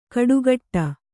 ♪ kaḍugaṭṭa